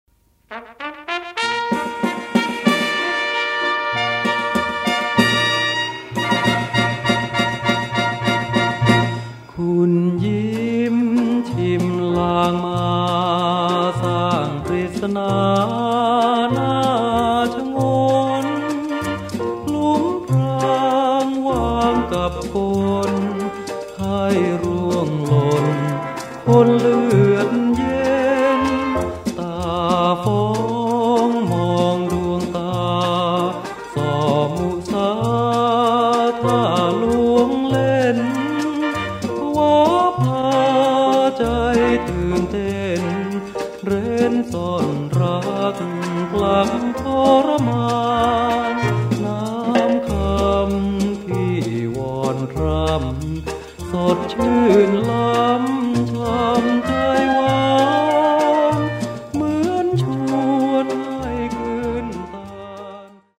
สินค้า / เพลงลูกกรุง ฟังสบายๆ คลายร้อน